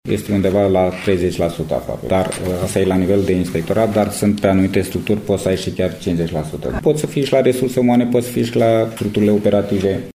Şeful Inspectoratului de Poliţie Judeţean Vaslui, Dan Pelin, a declarat, astăzi, în cadrul unei conferinţe de presă, că instituţia pe care o conduce funcţionează cu un deficit de personal de aproximativ 30%, însă sunt structuri în care numărul angajaţilor este chiar şi la jumătate din necesar.